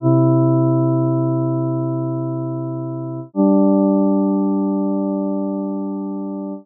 Both voices move in whole notes.
This is the sampled waveform for the two voices played together.
Counterpoint!